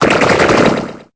Cri de Tarenbulle dans Pokémon Épée et Bouclier.